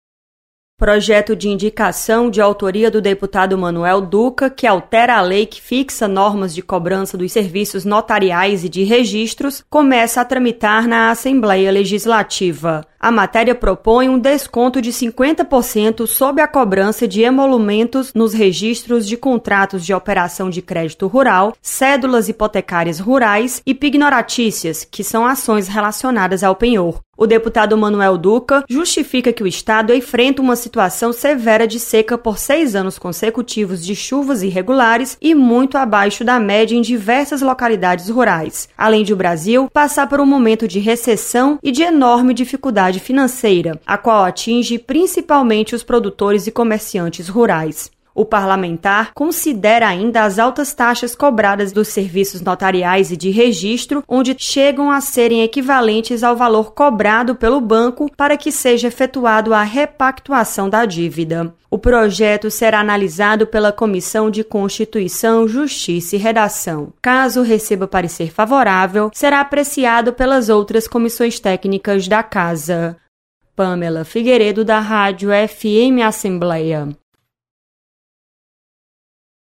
Deputado propõe descontos nas taxas de registro cobradas aos produtores rurais. Repórter